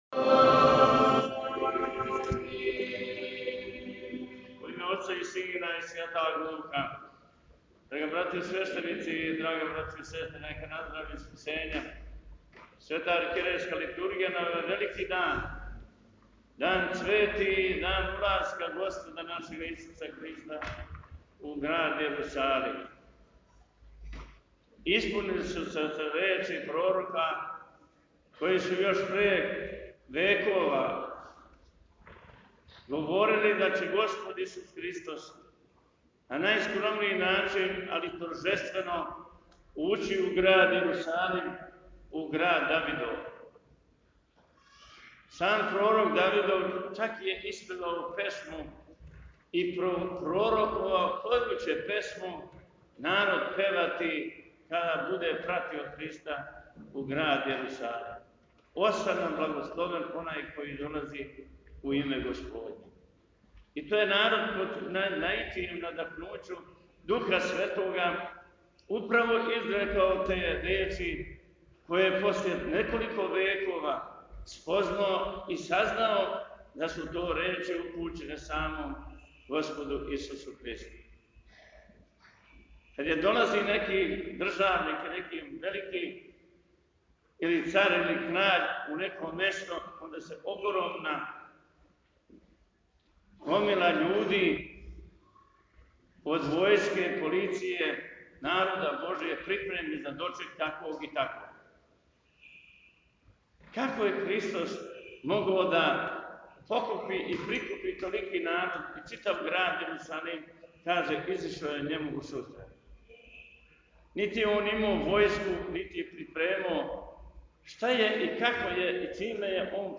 Беседа Епископа сремског г. Василија
beseda-na-cveti.mp3